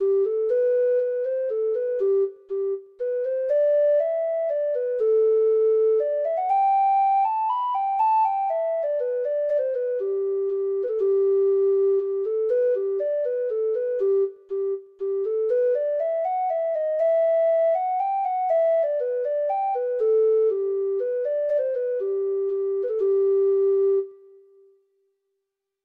Free Sheet music for Treble Clef Instrument
Traditional Music of unknown author.